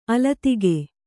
♪ alatige